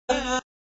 عنوان : سبک سرود میلاد حضرت زینب (س)